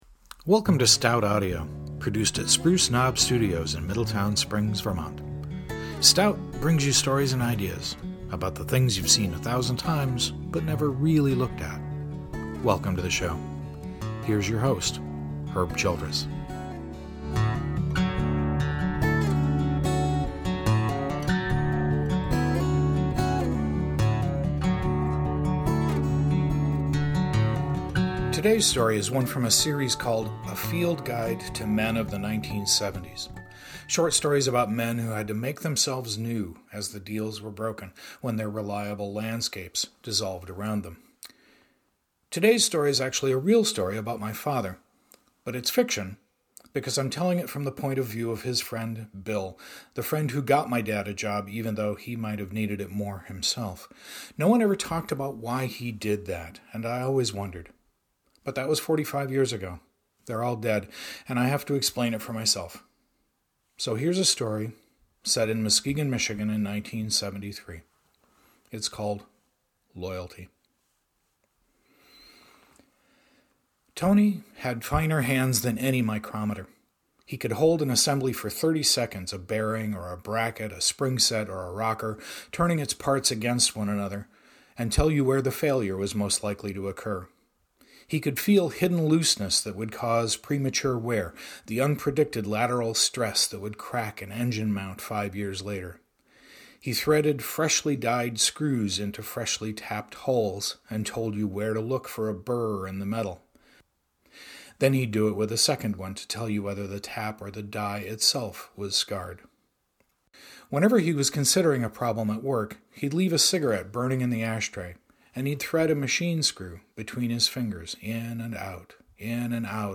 As part of my current project to build some of my work in audio format, I’ve been on a fast-paced, self-guided tutorial through the world of GarageBand, the sound mixing software that’s integrated with the Mac operating system.
In the spirit of exploration, here’s an audio version of one of my stories, “Loyalty.”